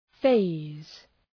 Προφορά
{feız}